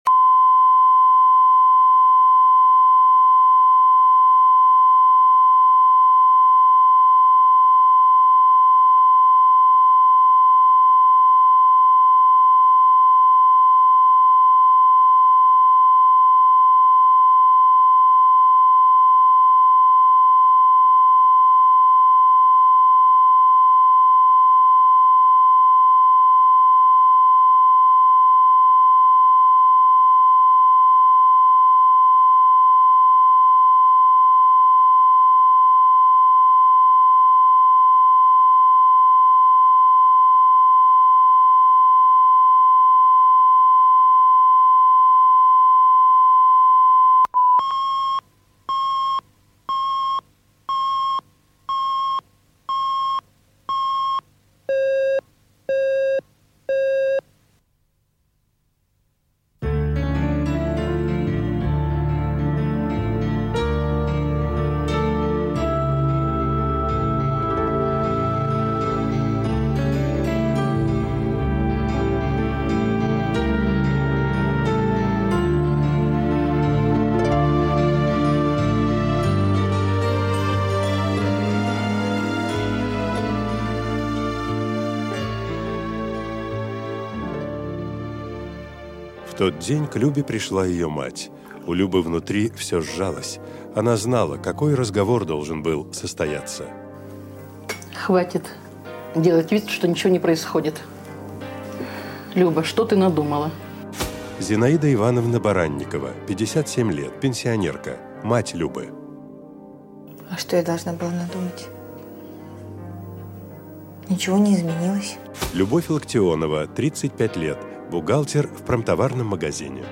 Аудиокнига Жена геолога | Библиотека аудиокниг